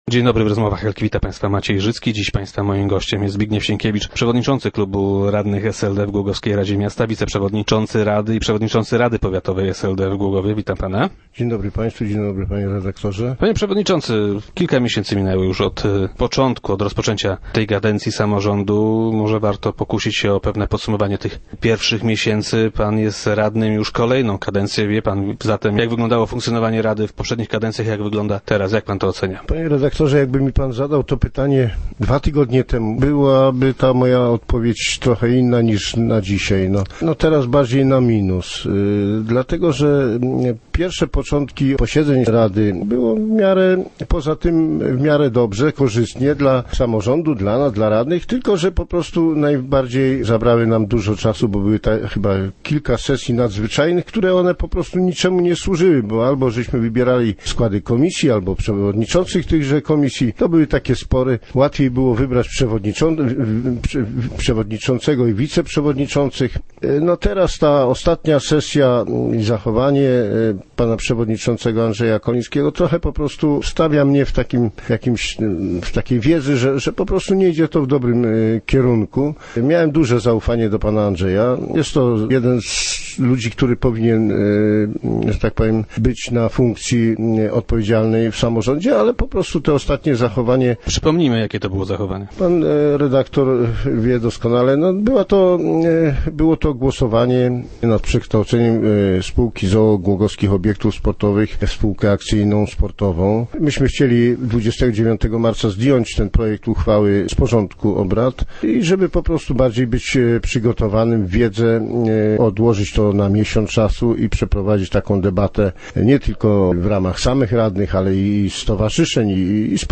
1012_sienkiewicz_do_rozmw.jpg- Zapowiadało się, że bezie dobrze, ale widzę, że nie zmierza to w dobrym kierunku – tak radny SLD Zbigniew Sienkiewicz podsumowuje pierwsze miesiące funkcjonowania nowej rady miasta. Przewodniczący klubu SLD był gościem piątkowych Rozmów Elki.